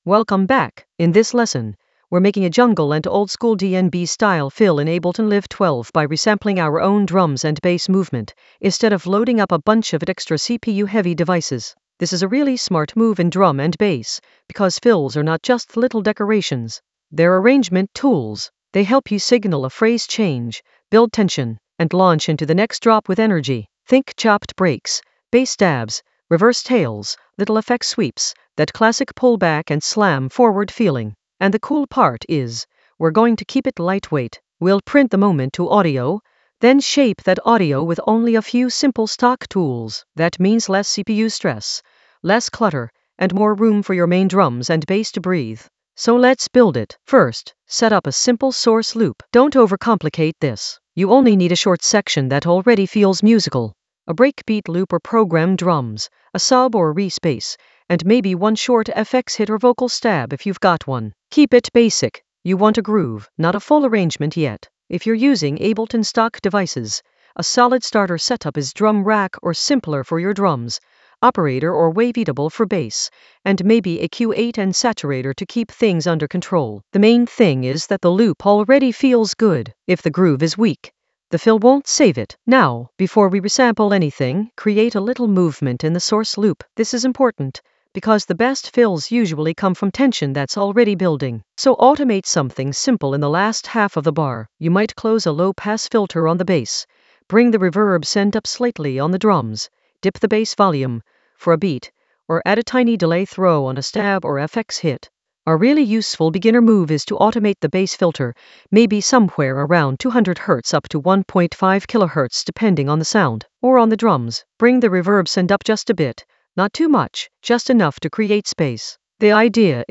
An AI-generated beginner Ableton lesson focused on Fill in Ableton Live 12: resample it with minimal CPU load for jungle oldskool DnB vibes in the Automation area of drum and bass production.
Narrated lesson audio
The voice track includes the tutorial plus extra teacher commentary.